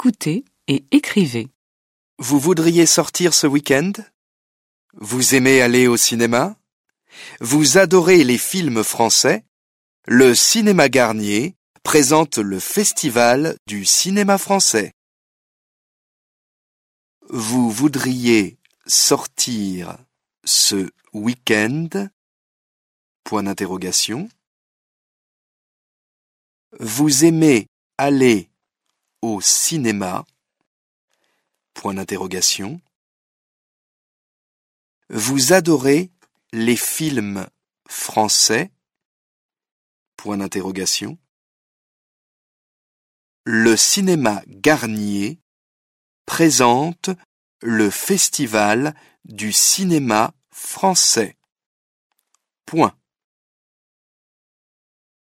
دیکته - مبتدی